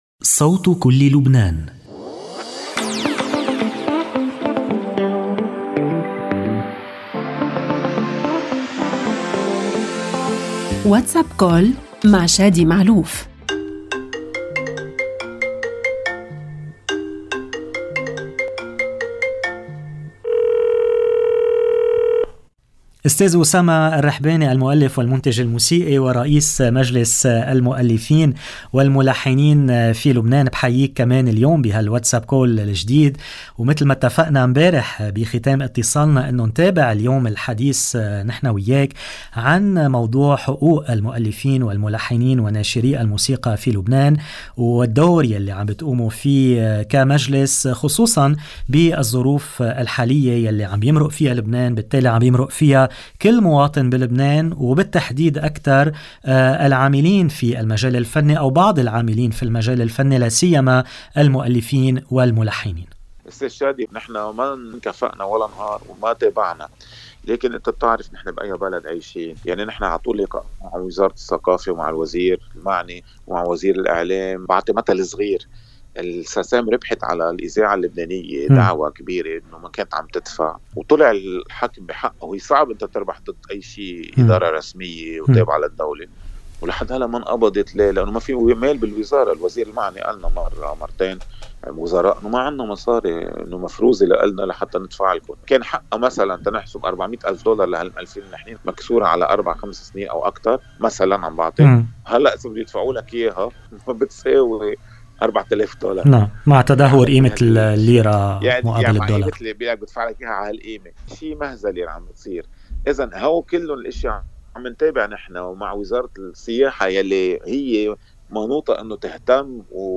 WhatsApp Call رئيس مجلس المؤلفين والملحنين وناشري الموسيقى في لبنان المؤلف والمنتج الموسيقي أسامة الرحباني ٢/٢ Apr 16 2024 | 00:16:59 1x 00:00 / 00:16:59 Subscribe Share RSS Feed Share Link Embed